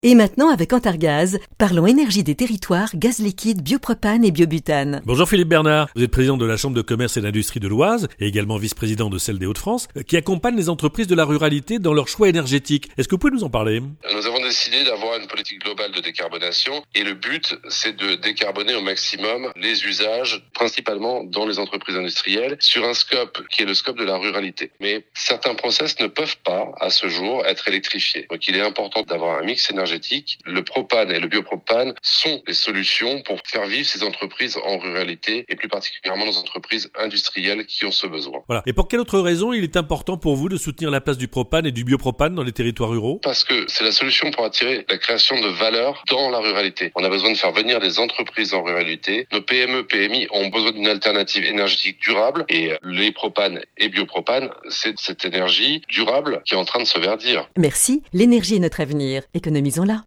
Nos spots radio autour de la thématique de la décarbonation des territoires